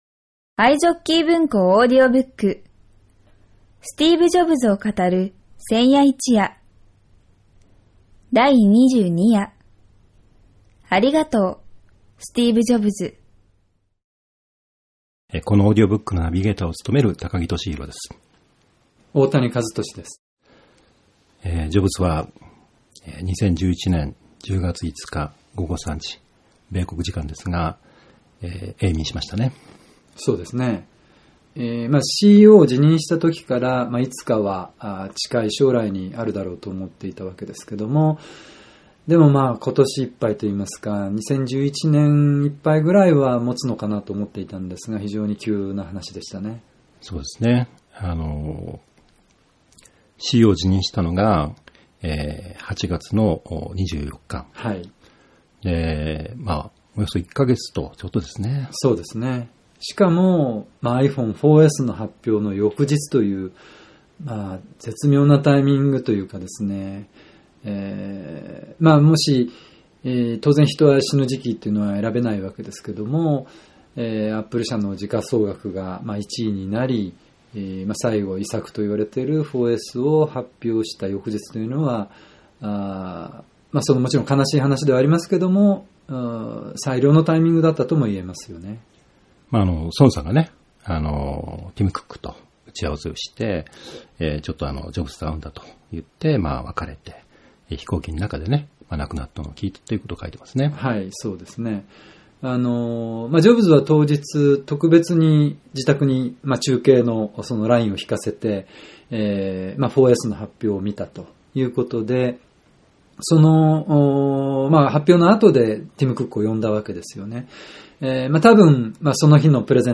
[オーディオブック] スティーブ・ジョブズを語る千夜一夜 第22夜